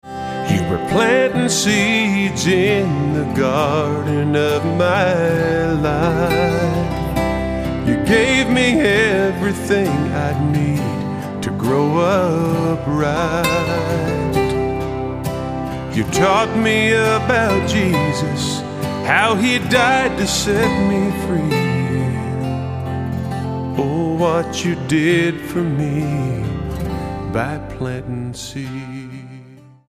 STYLE: Country
crisp production featuring steel guitar, dobros and fiddles